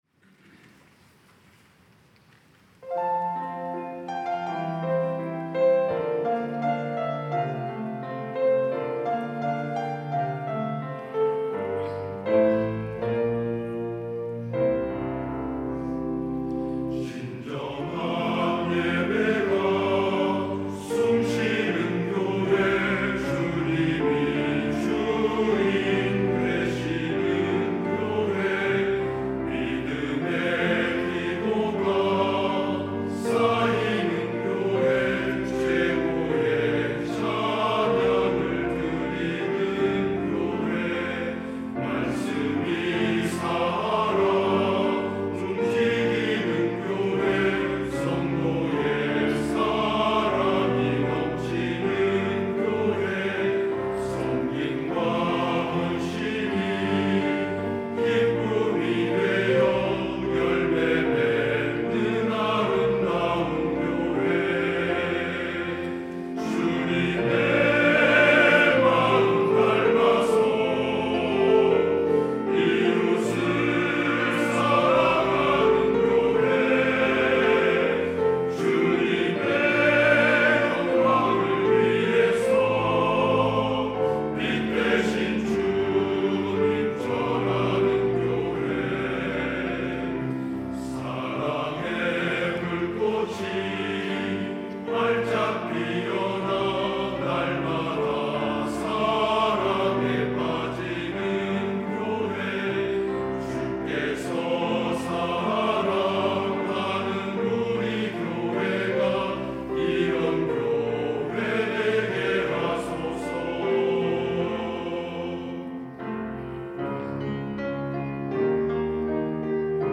찬양대 남선교회